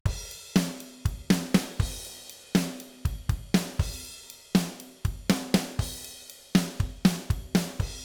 оригинал ударных в аттаче )